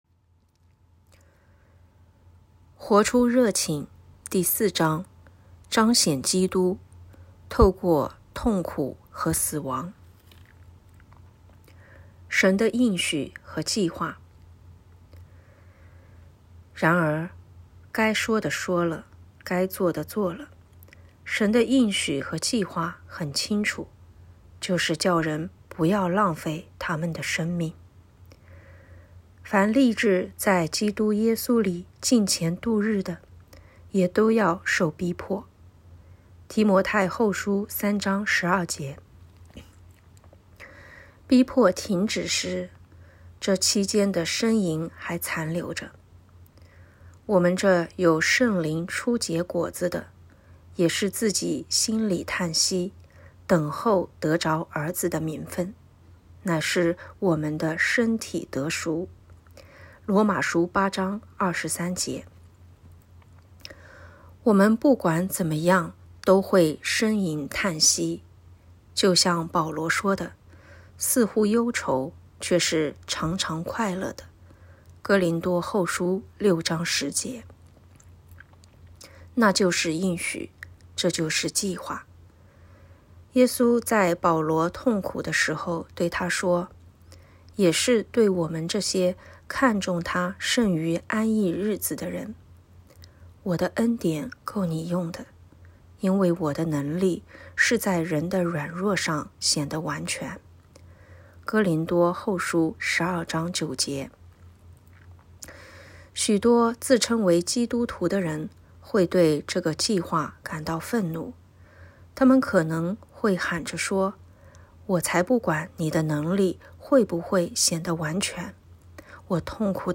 2024年1月4日 “伴你读书”，正在为您朗读：《活出热情》 音频 https